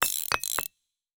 Rocket_upgrade (3).wav